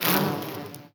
fly_buzz_flying_01.wav